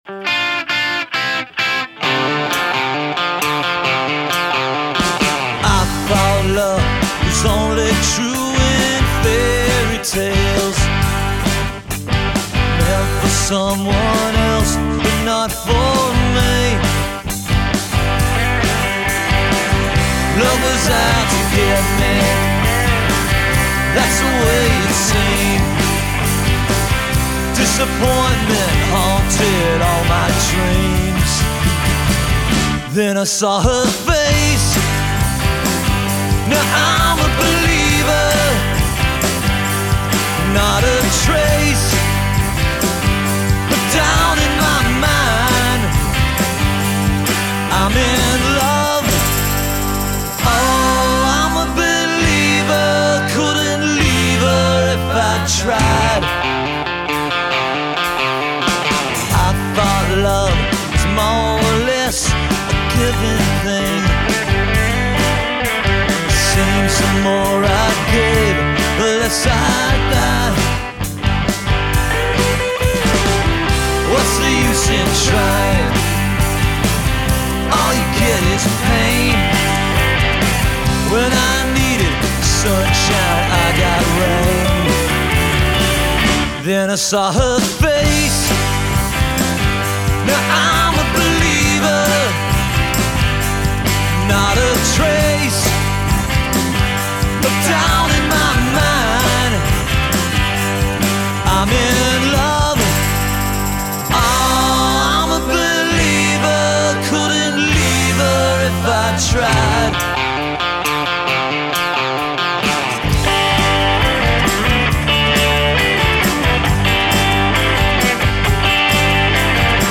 Vocal, Guitars
Bass
Drums
Adamo’s Recording-Westminister, CA